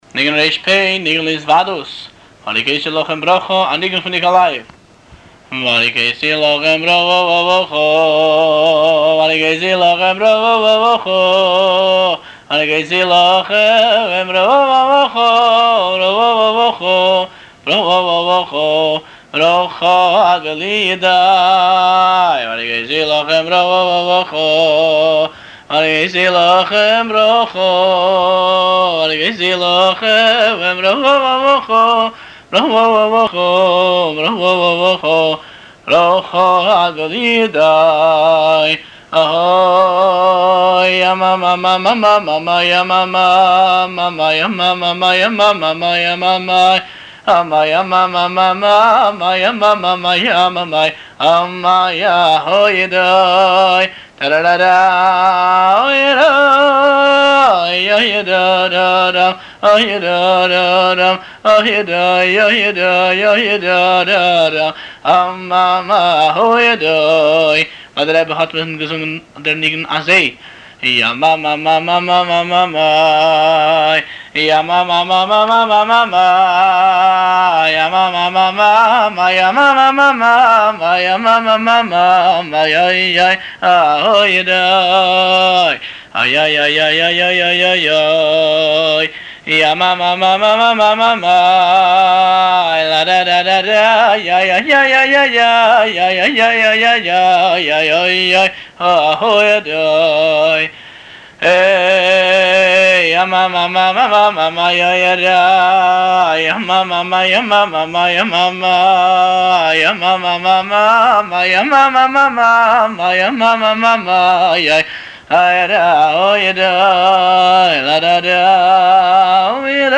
הניגון